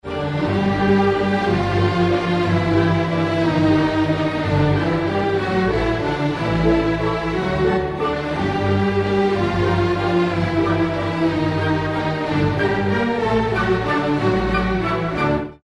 mysterious